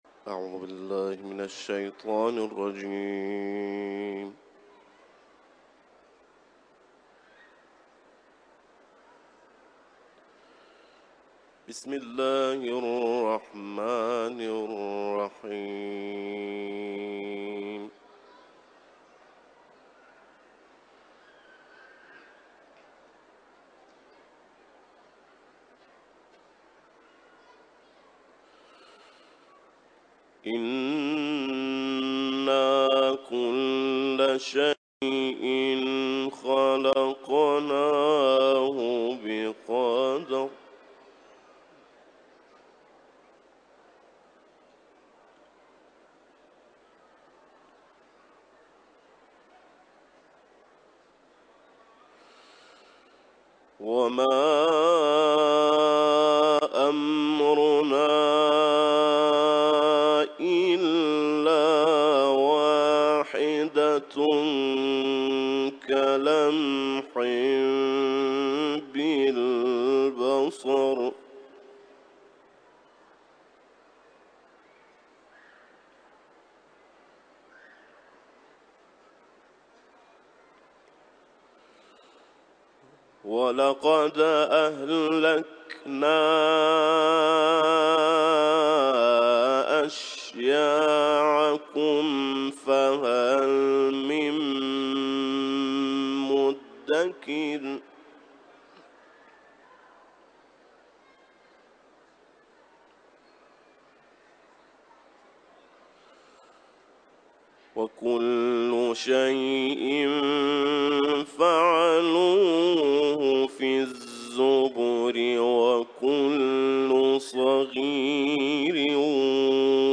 IQNA – Uluslararası İranlı kâri Kur’an-ı Kerim’in Kamer ve El-Rahman surelerinden ayetler tilavet etti.